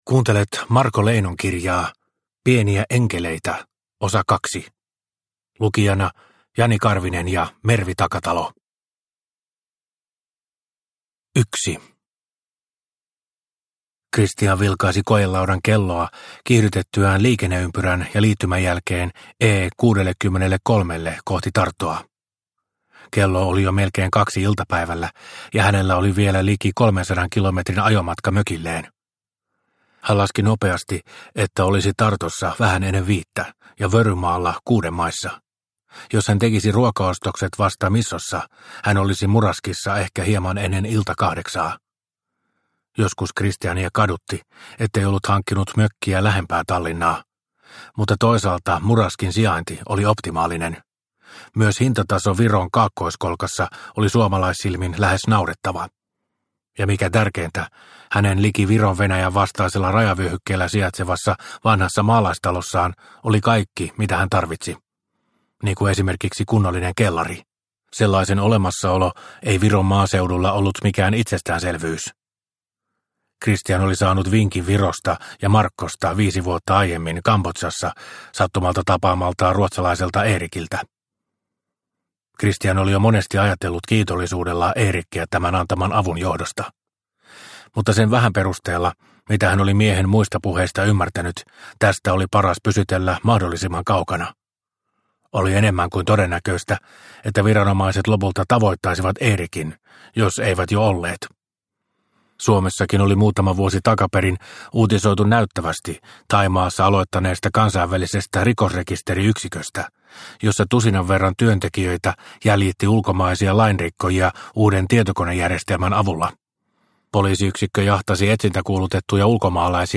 Pieniä enkeleitä – Ljudbok – Laddas ner